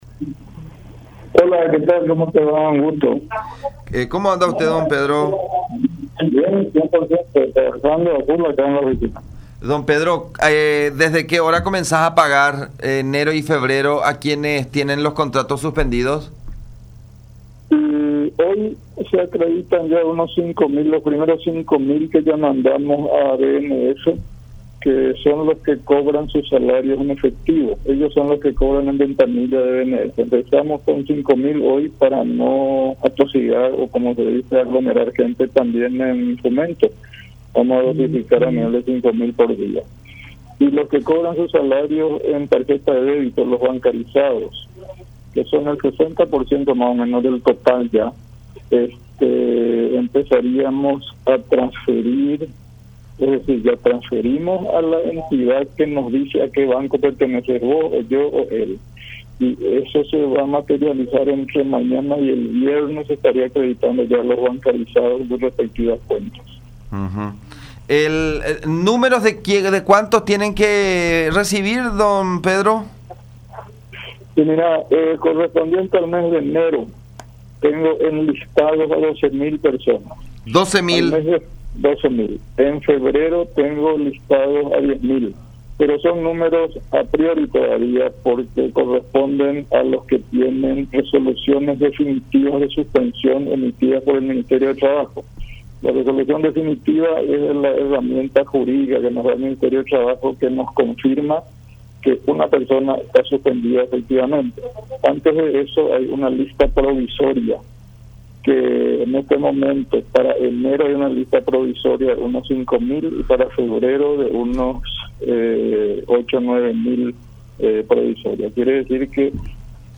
en contacto con La Unión R800 AM.